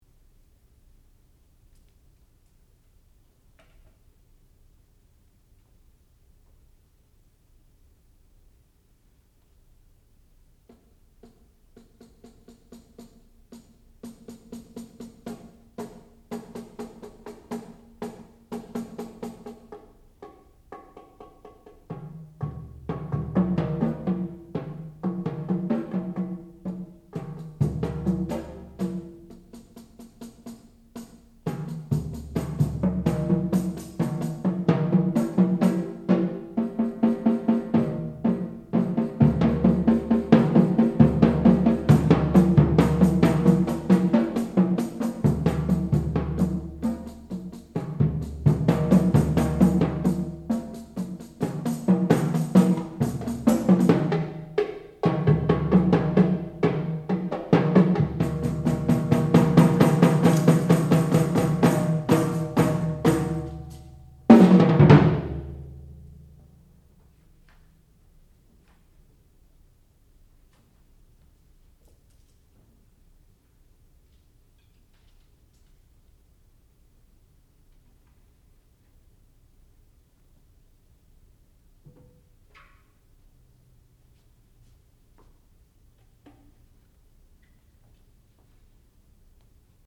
sound recording-musical
classical music
percussion
piano
Junior Recital